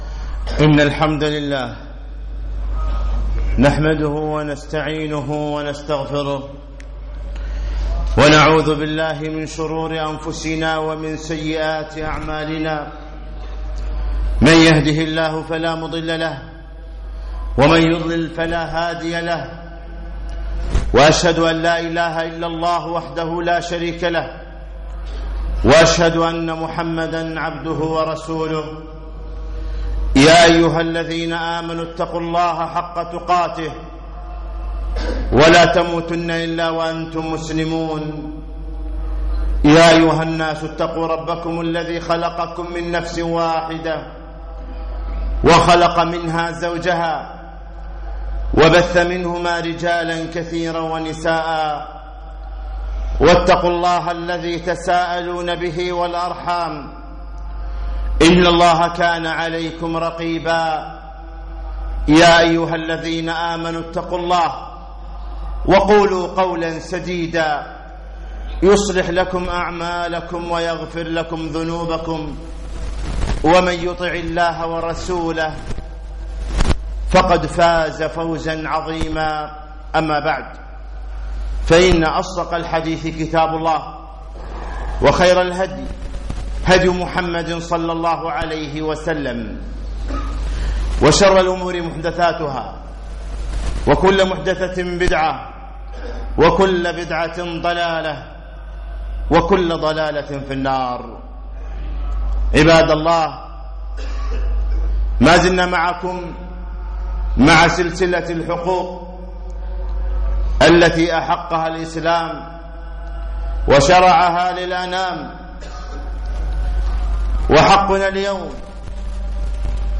الجمعة 26 ربيع الثاني 1437 الموافق 5 2 2016 مسجد عطارد بن حاجب الفروانية